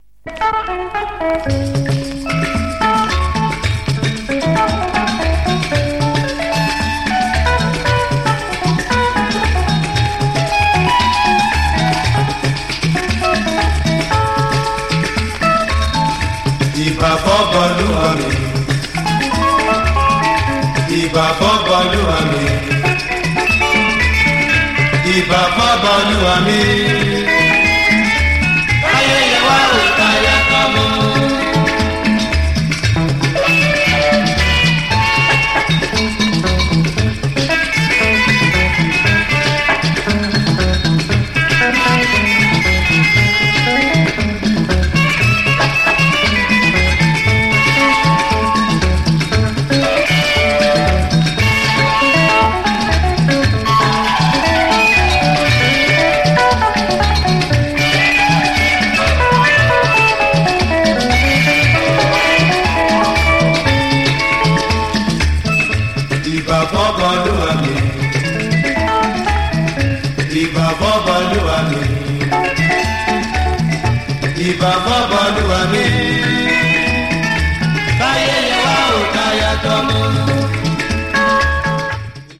1988年の録音になるそうです（不確かですが）。が、なるほど、音が新しい、ヴォーカルも実に落ち着いた感じ。